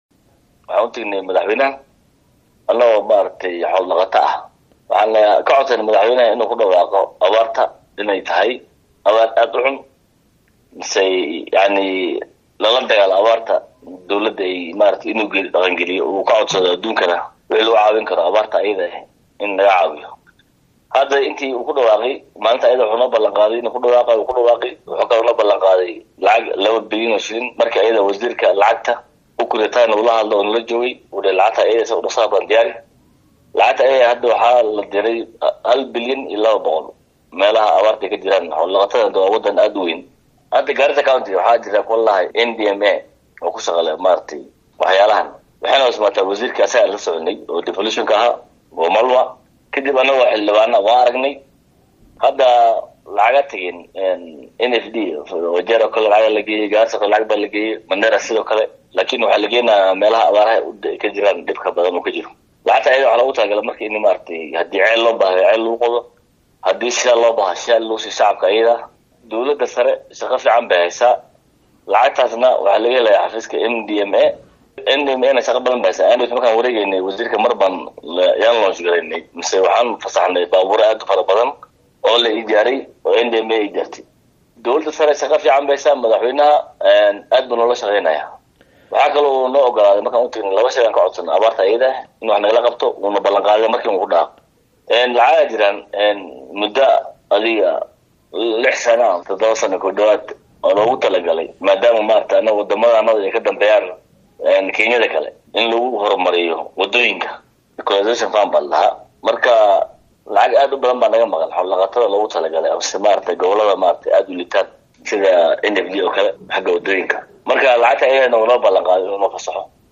Dawlada ayaa wada dadaala lagu caawinaya dadka ay abaarta samaysay ee xoola dhaqatada ah . Arinatan ayaa idaacada STAR FM faah faahin ka siiyay xildhibaaka laga soo doortay kursiga Barlamaaneedka ee FAFI ee ismamaulka Garissa.